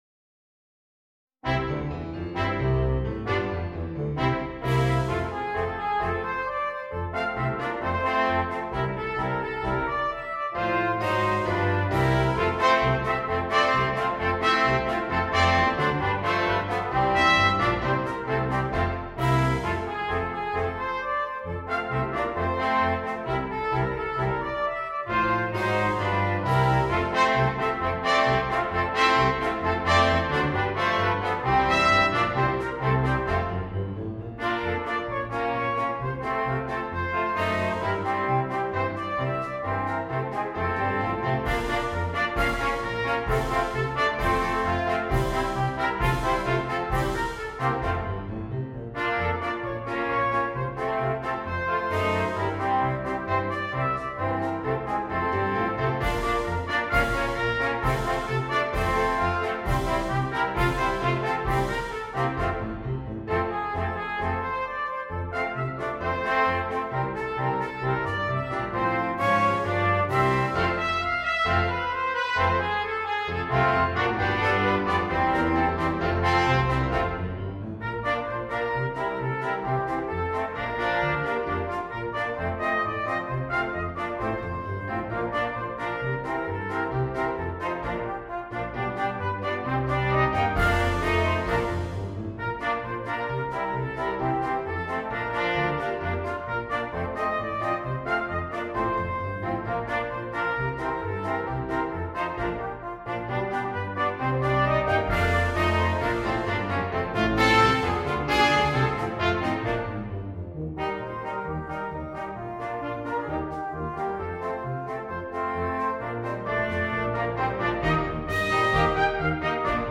Gattung: Marsch für 4-stimmig gemischtes Ensemble
Besetzung: Ensemble gemischt